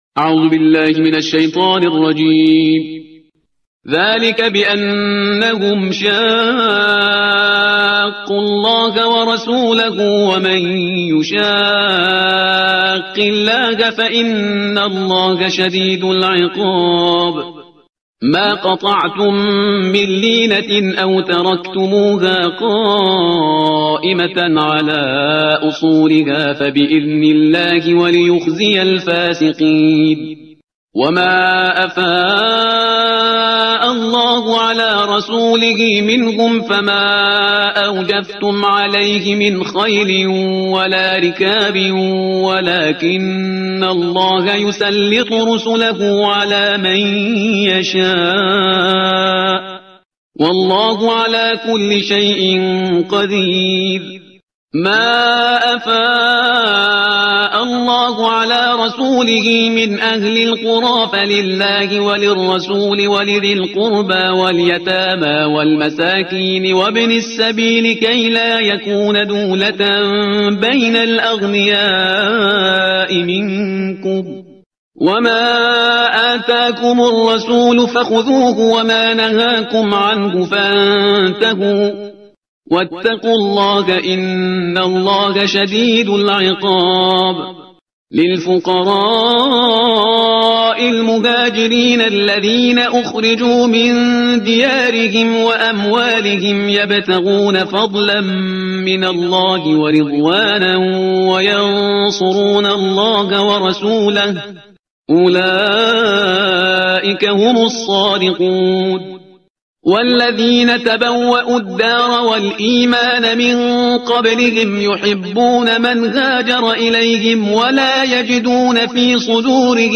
ترتیل صفحه 546 قرآن با صدای شهریار پرهیزگار